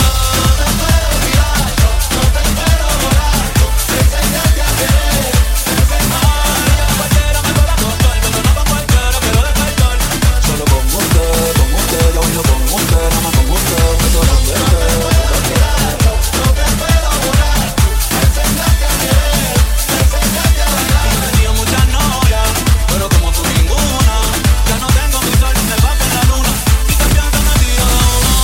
Genere: tribal,anthem,circuit,remix,hit